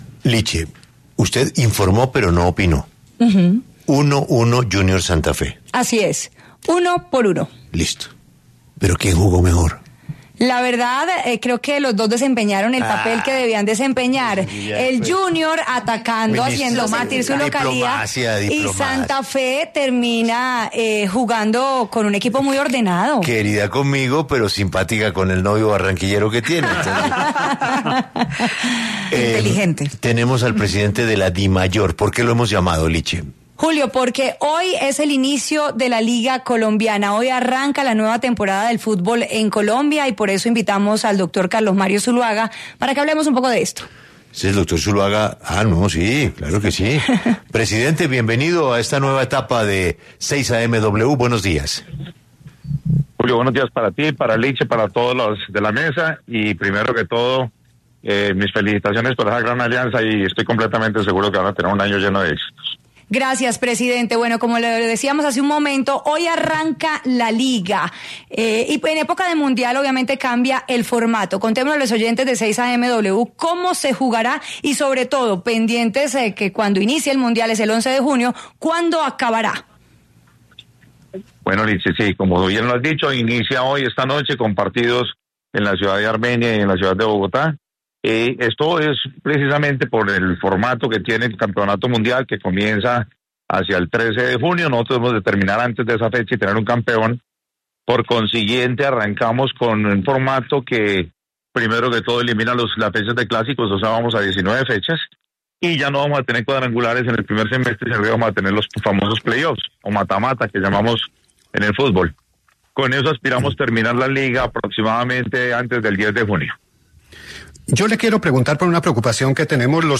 Este viernes, 16 de enero, habló en los micrófonos de 6AM W, con Julio Sánchez Cristo, el presidente de la Dimayor, Carlos Mario Zuluaga Pérez, quien se refirió a varios temas coyunturales del Fútbol Profesional Colombiano, a propósito del arranque de la Liga BetPlay 2026 - I.